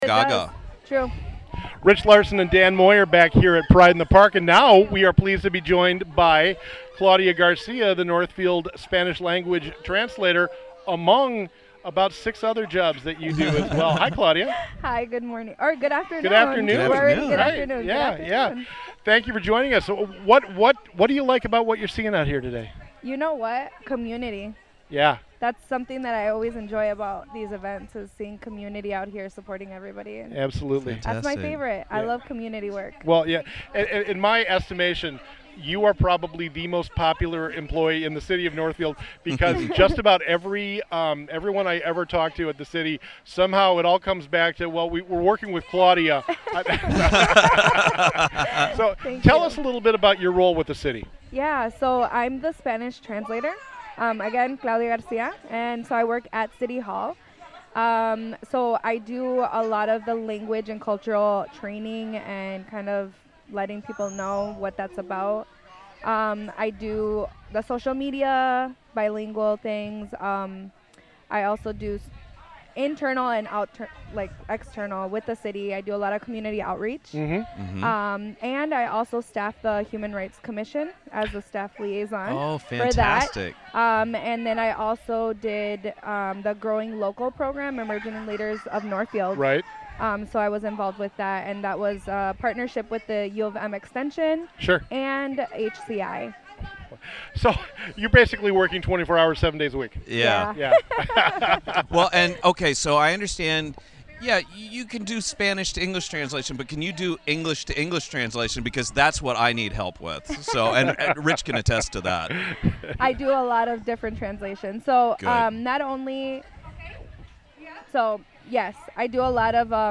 discusses issues of diversity and inclusion from Northfield’s Pride in the Park celebration.